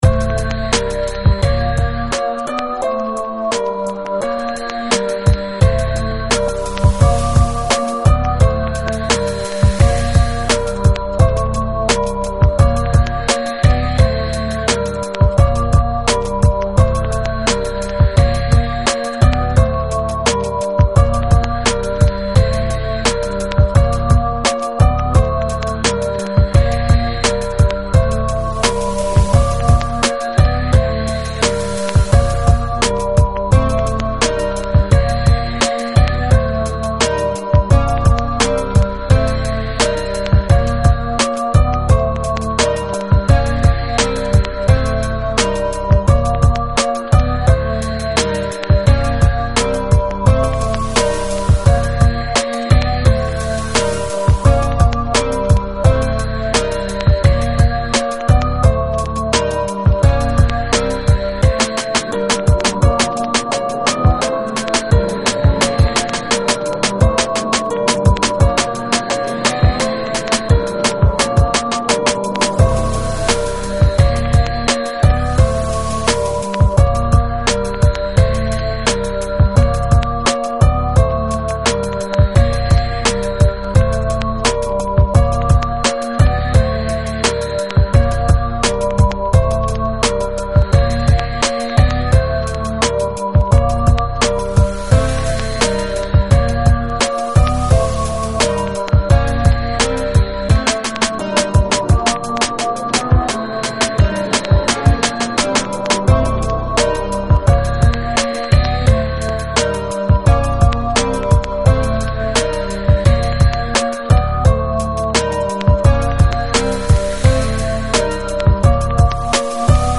Рэп (46715)